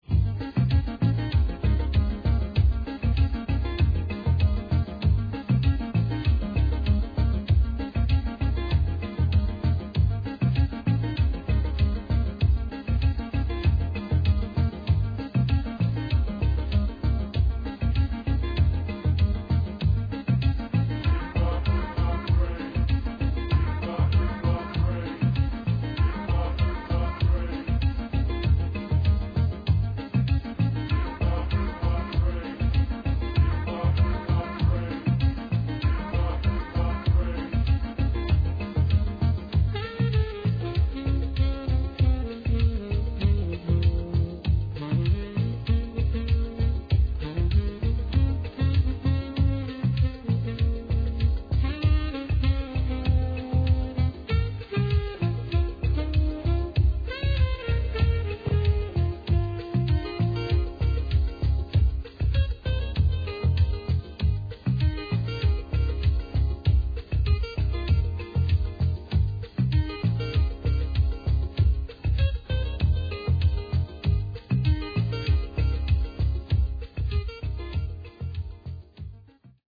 Dub tracks and instrumentals work best.